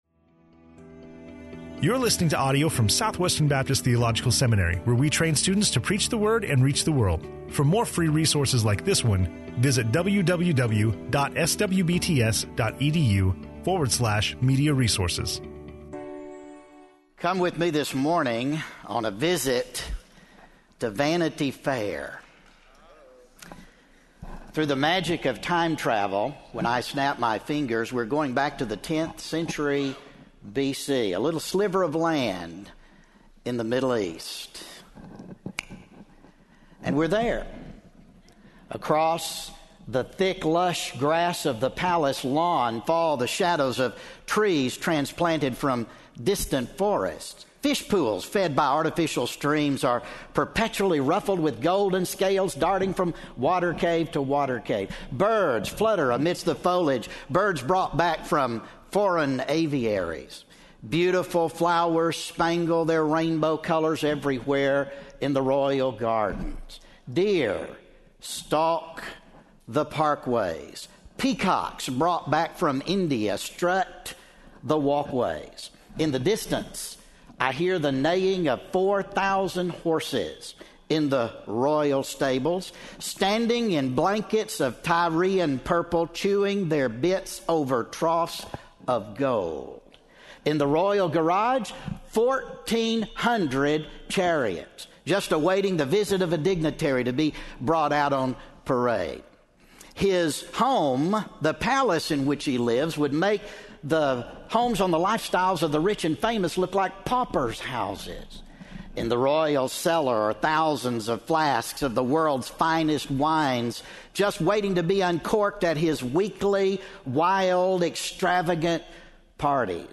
speaking on Ecclesiastes 1:1-2, 12:9-14 in SWBTS Chapel on Thursday October 10